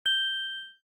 cam_timer_1sec.ogg